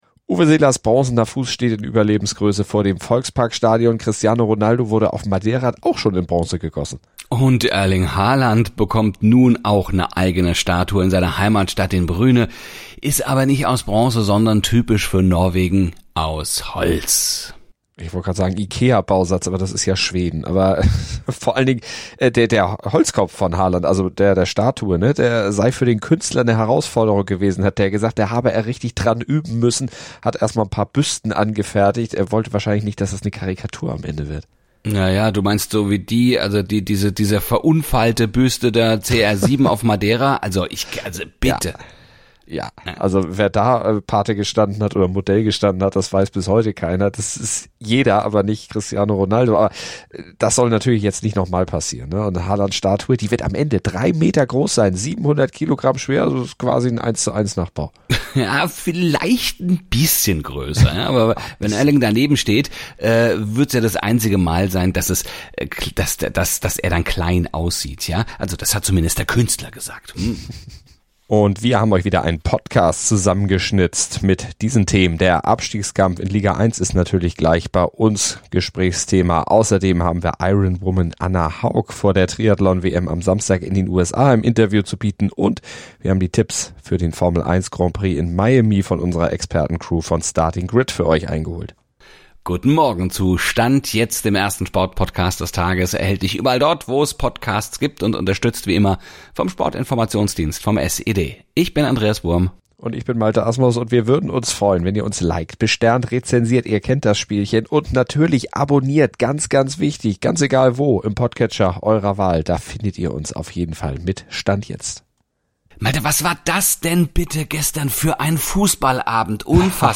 Und sie habenIronwoman Anna Haug vor der Triathlon-WM am Samstag in den USA im Interview und Tipps für den Formel 1-GP in Miami bei der Expertencrew von Starting Grid eingeholt.